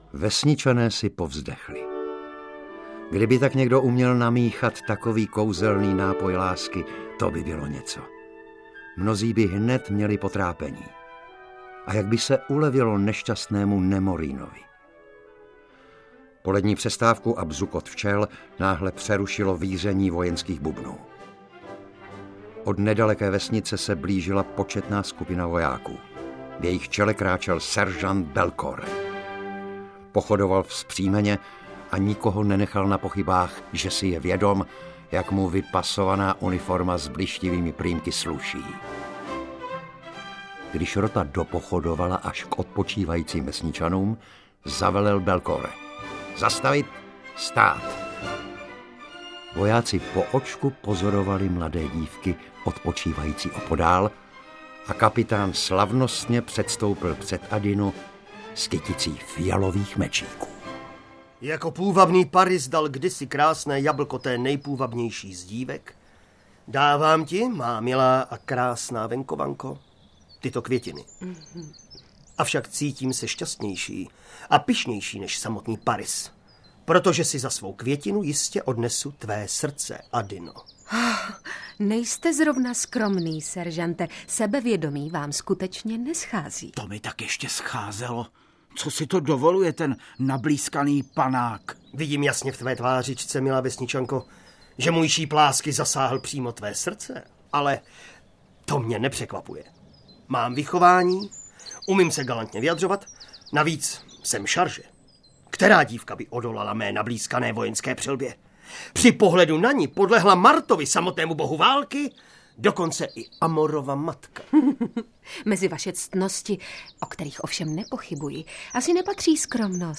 Slavný operní příběh